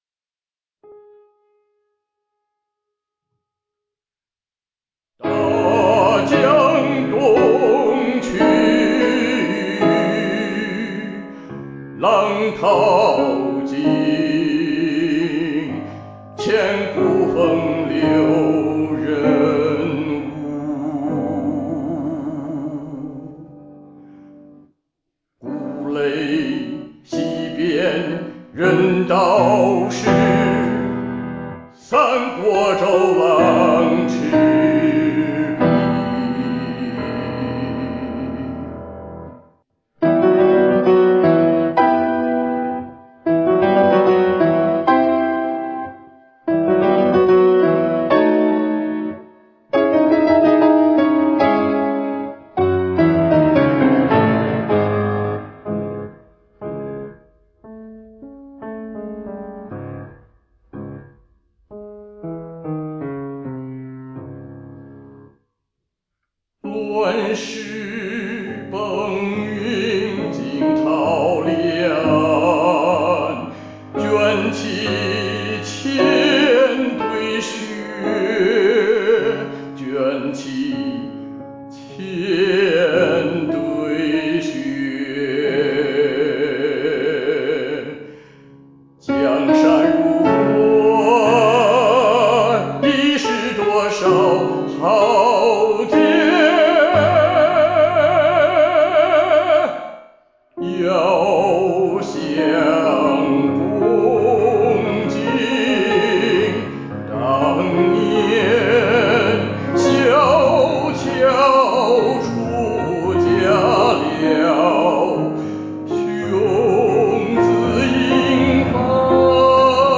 带病高歌，看豪情是否有减？
《大江东去》和声运用新颖大胆，富于运用戏剧性朗诵与抒情性咏叹相结合的音调和非常钢琴化的伴奏，以营造出磅礴的气势和意境深远的音乐形象，该作品开拓了德奥艺术歌曲体系在中国大陆融合发展的新阶段。